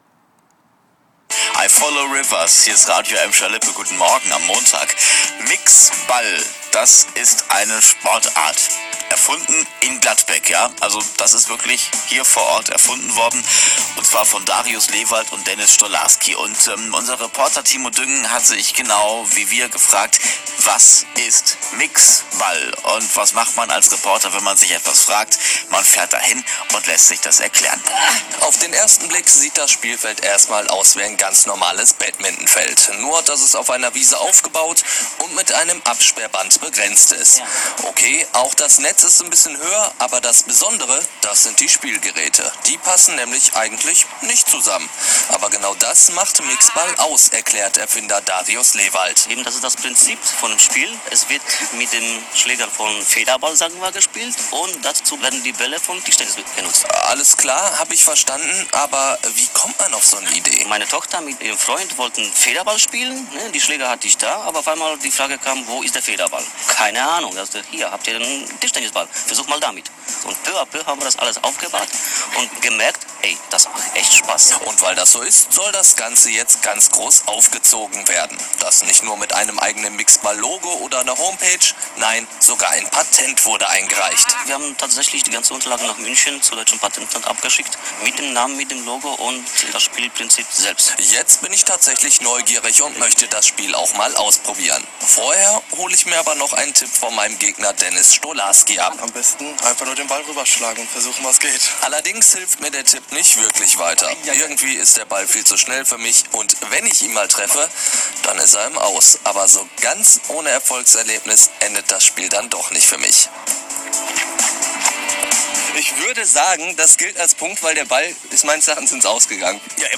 Radioreportage